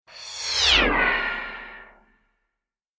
جلوه های صوتی
دانلود صدای کشتی 1 از ساعد نیوز با لینک مستقیم و کیفیت بالا